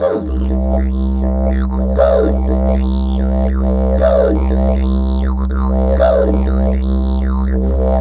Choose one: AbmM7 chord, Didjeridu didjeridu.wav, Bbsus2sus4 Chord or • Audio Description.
Didjeridu didjeridu.wav